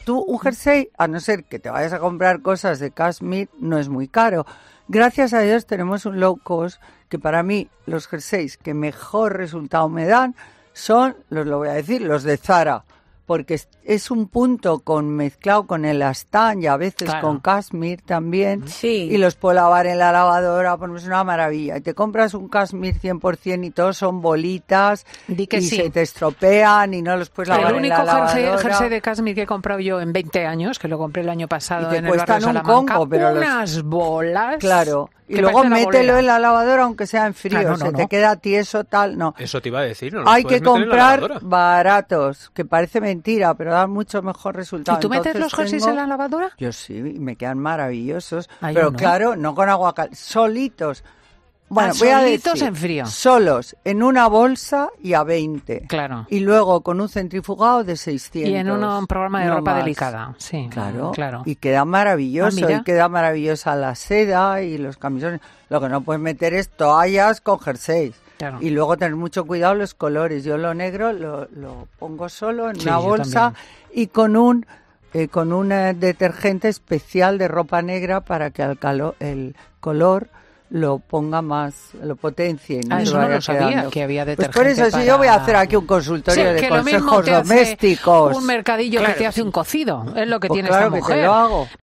Aunque Schlichting, siempre atenta a lo que dicen sus colaboradores en 'Fin de Semana COPE' le ha hecho una pregunta muy relevante.
"Yo lo negro lo pongo solo, en una bolsa, y con un detergente especial de ropa negra para que el color lo potencie", algo que Schlichting parecía no conocer: "Yo no sabía que había detergentes así", lo que ha provocado que Lomana lance una seria advertencia en pleno directo: "¡Si es que yo voy a hacer aquí un consultorio de consejos domésticos!", estaremos atentos a ver si cumple con su amenaza.